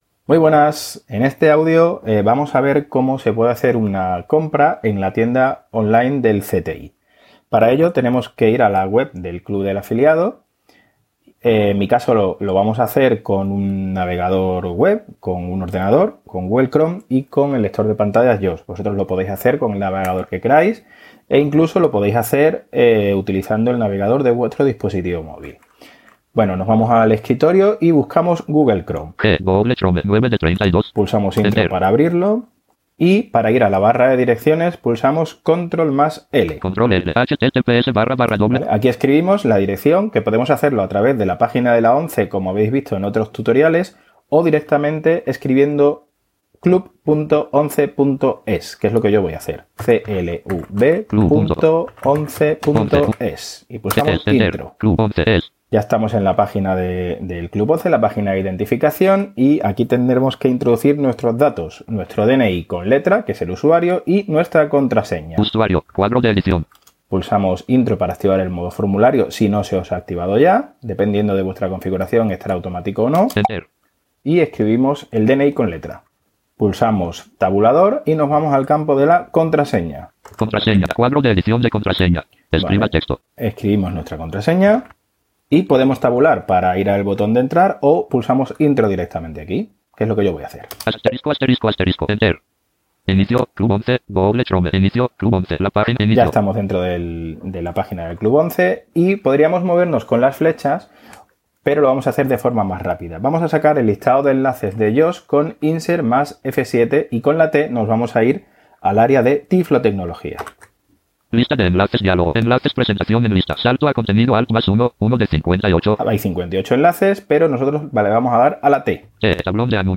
Tutorial_uso_tienda_CTI_Online.mp3